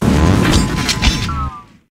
armarouge_ambient.ogg